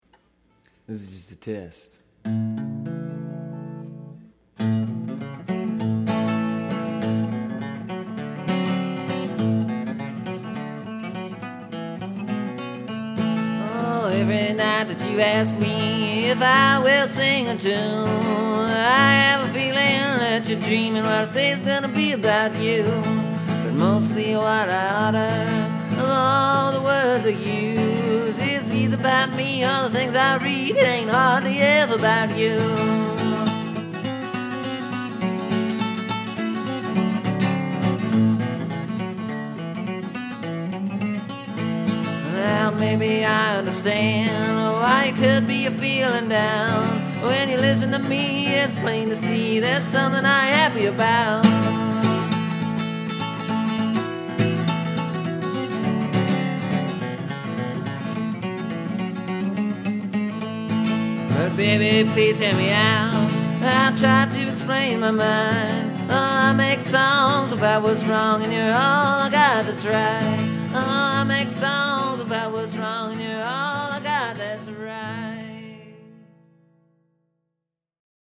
In this new recording I used a guitar lick that I play all the time.
This new one is kinda over-the-top, or something.
There was a jackhammer tearing up the road in the background when I recorded this.
But that road drill kinda just settles in nicely to the background, don’t ya think?